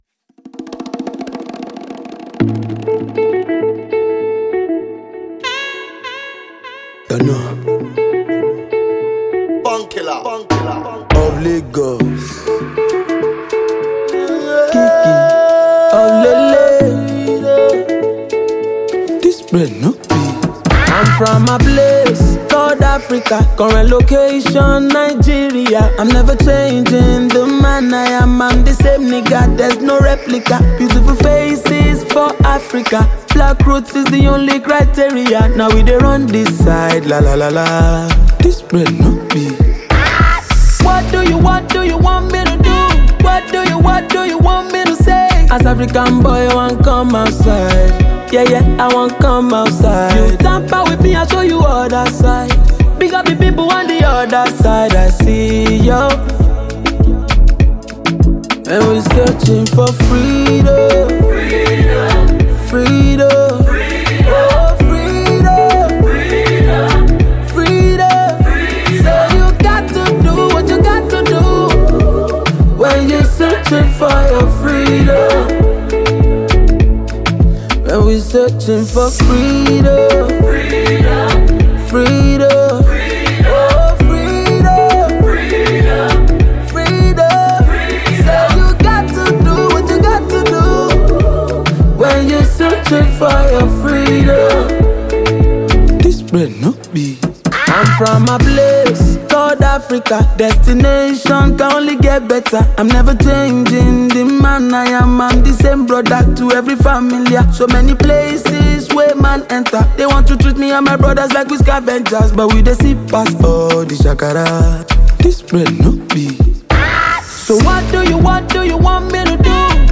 A-List Nigerian singer and songwriter